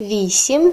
Added Ukrainian numbers voice files